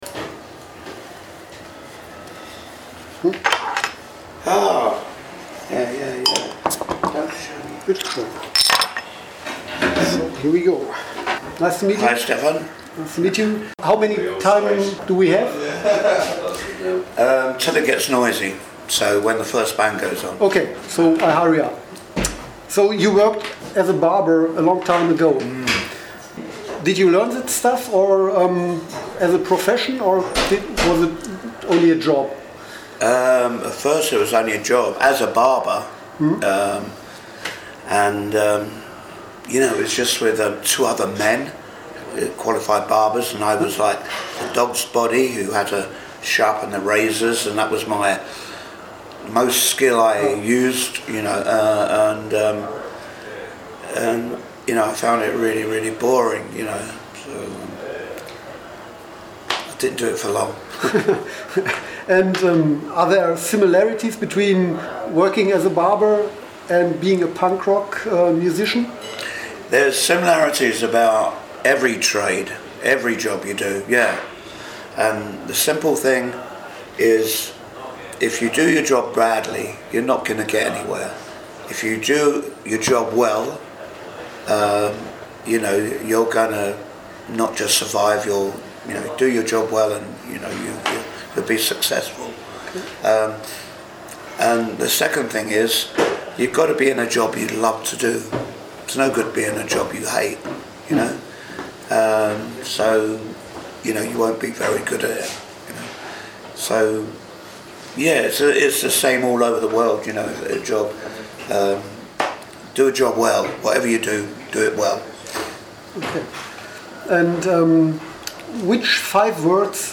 interview-mit-charlie-harper-uk-subs-mmp.mp3